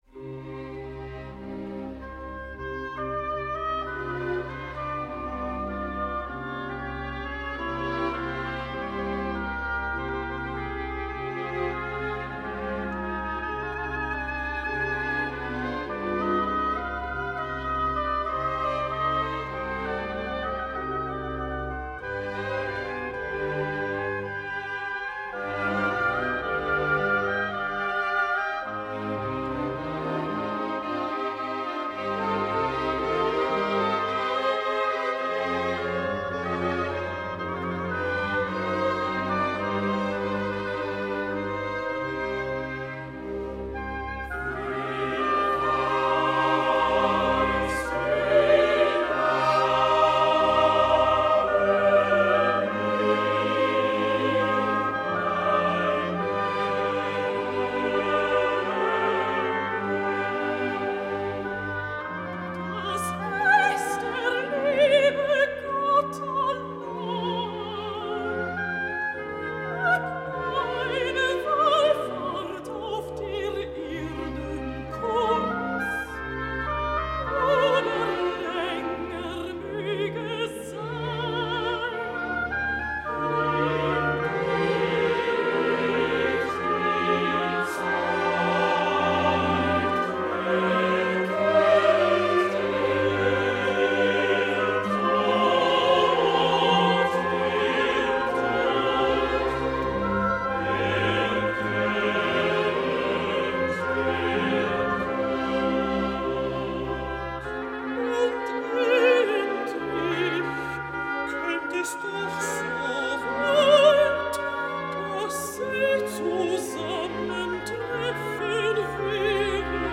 Wer weiß, wie nahe mir mein Ende- Coro e Recitativo (S,A,T)